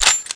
assets/nx/nzportable/nzp/sounds/weapons/ppsh/boltrelease.wav at 1ef7afbc15f2e025cfd30aafe1b7b647c5e3bb53
boltrelease.wav